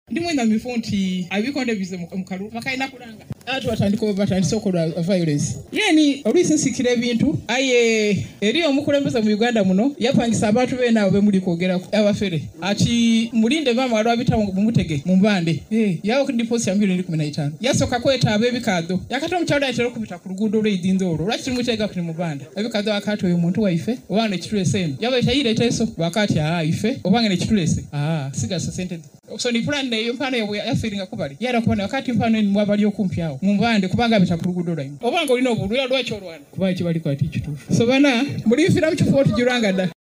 Speaking at a giveback to the elders event in Kamuli on Tuesday, Kadaga alleged that the official, whom she declined to name, had hired assassins to eliminate her due to her outspoken criticism of the government.